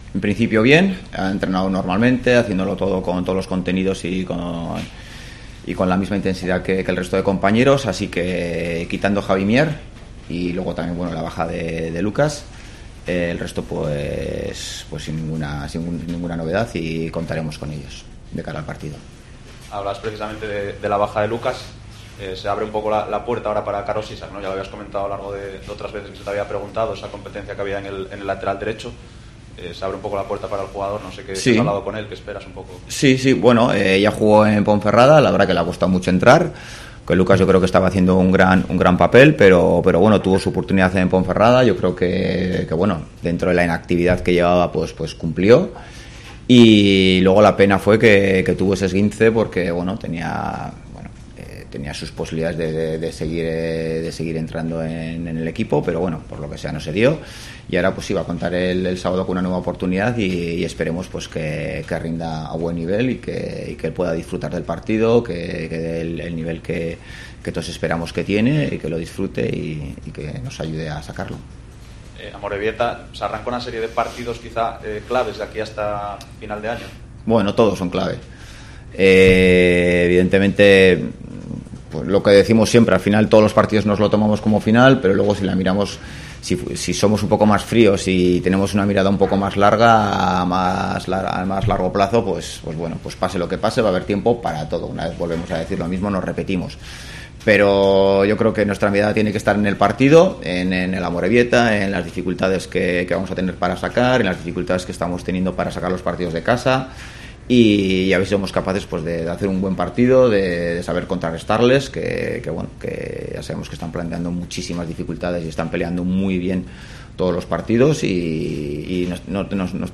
Rueda de prenda Ziganda (Oviedo-Amorebieta)